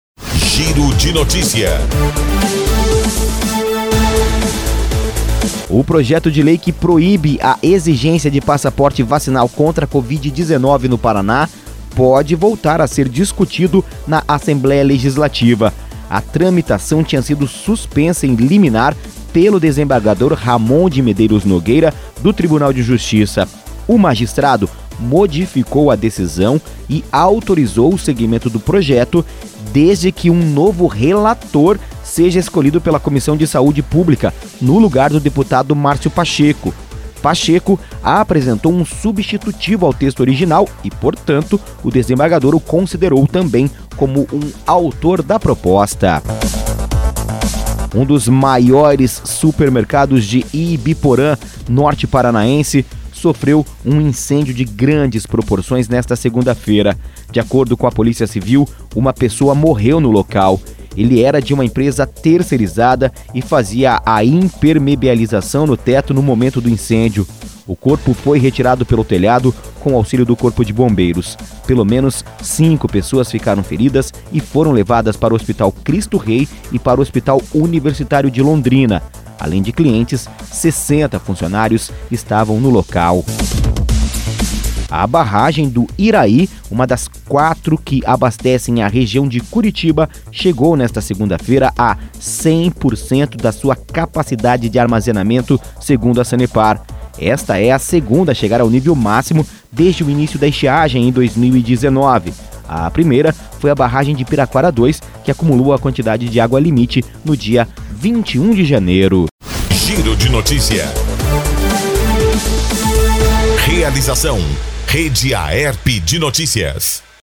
Associação das Emissoras de Radiodifusão do Paraná